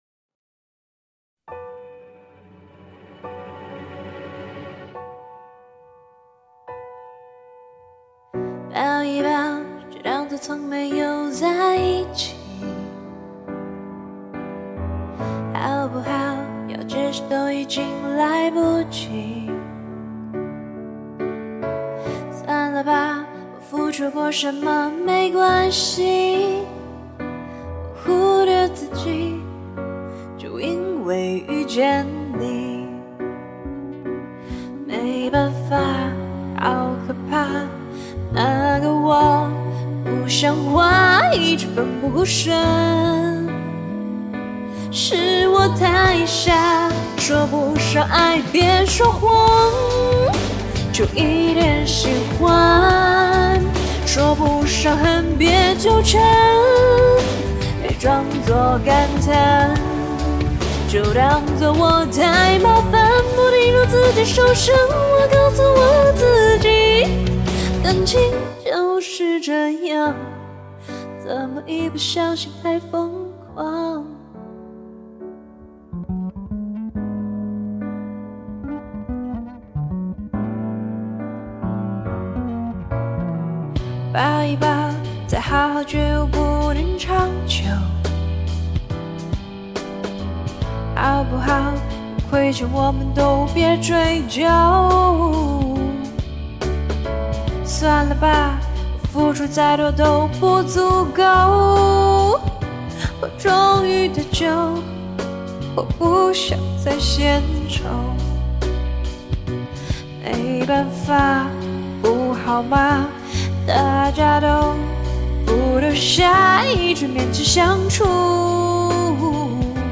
第一句听出萌感也是很厉害，声线明明如此的粗壮~~w
开头低副歌又高，跨越挺大的——唔，好像没一首歌曲简单23333
我的声线一直就很迷，就跟着曲风跑！